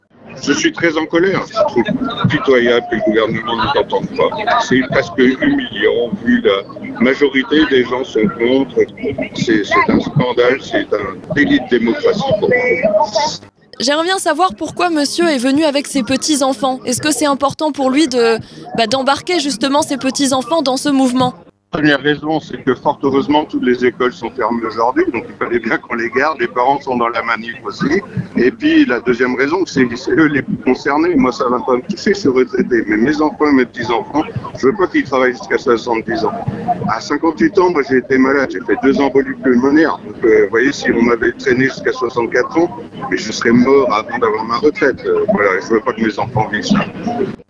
La rédaction de JAIME Radio a recueilli vos témoignages.